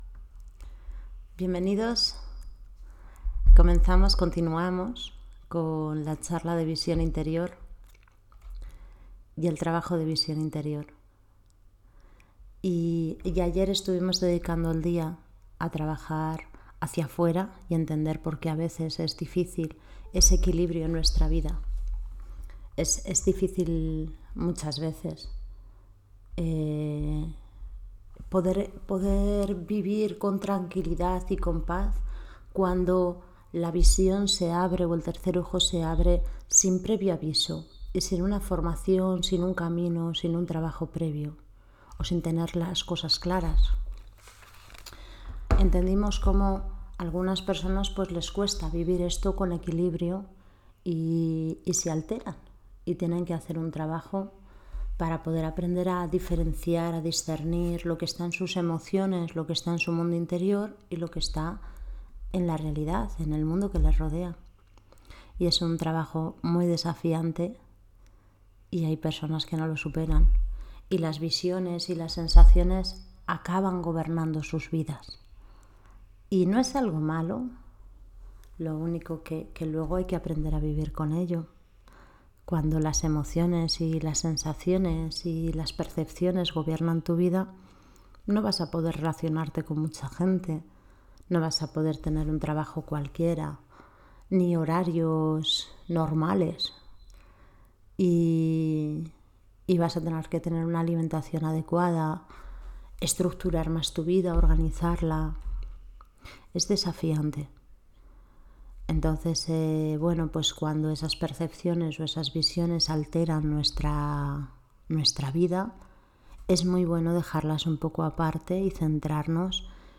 Charla encuentro sobre la Visión Interior